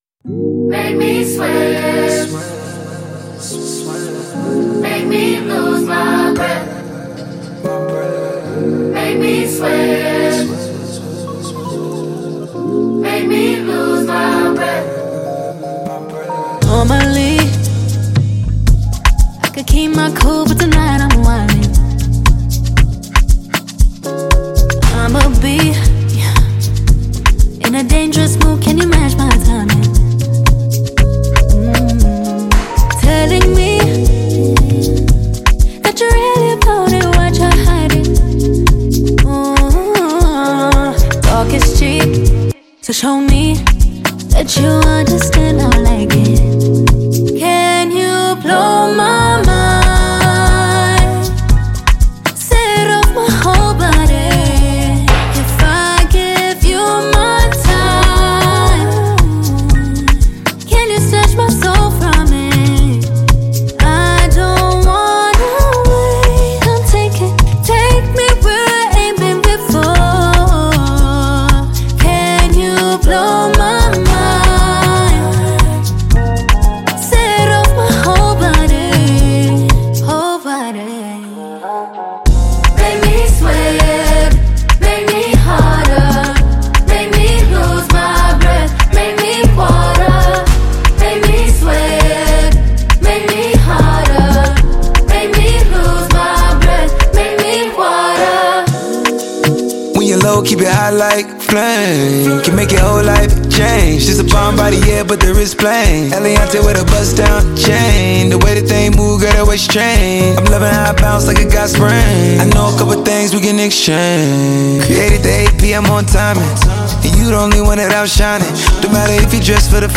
singer,songwriter, and media celebrity from South Africa